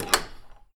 door_open.mp3